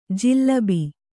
♪ jillabi